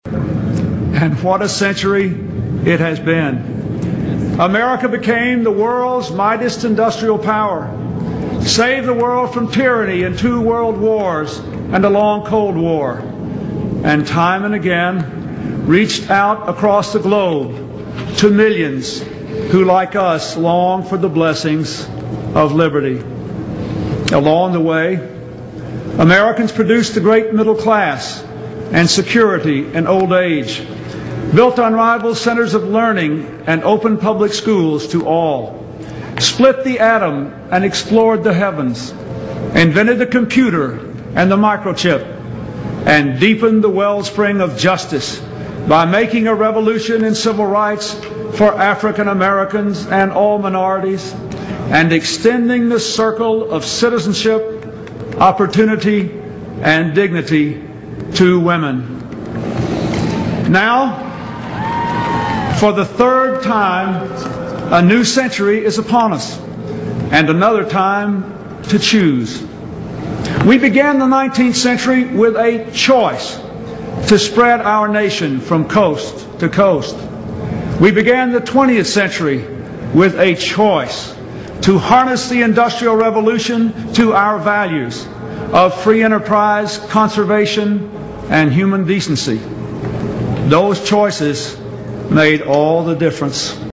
名人励志英语演讲 第12期:我们必须强大(2) 听力文件下载—在线英语听力室